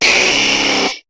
Cri de Dracaufeu dans Pokémon HOME.